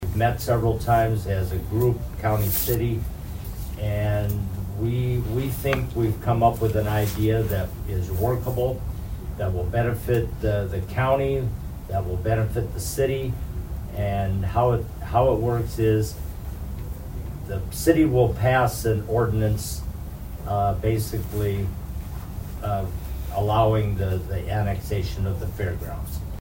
Brown County Commissioner Duane Sutton talks about how the process came about.